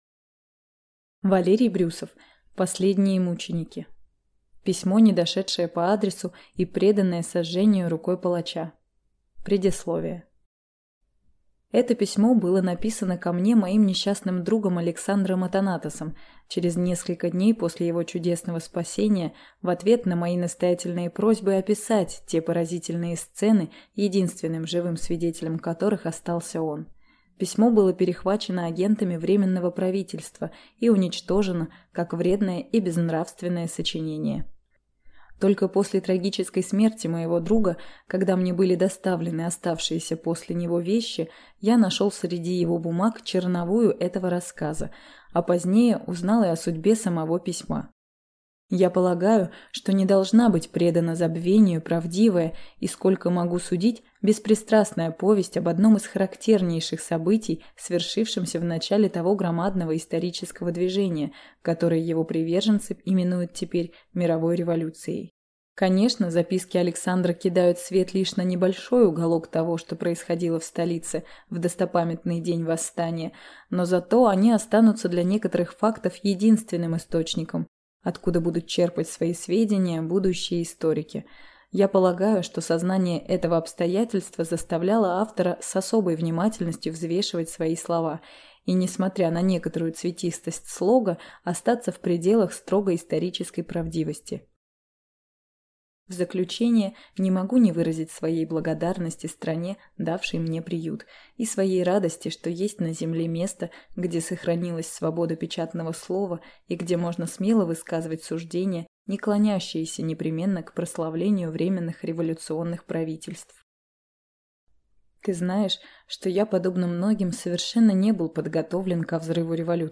Аудиокнига Последние мученики | Библиотека аудиокниг